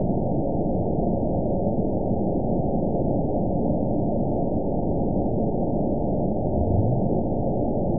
event 922710 date 03/17/25 time 18:54:16 GMT (1 month, 2 weeks ago) score 8.17 location TSS-AB02 detected by nrw target species NRW annotations +NRW Spectrogram: Frequency (kHz) vs. Time (s) audio not available .wav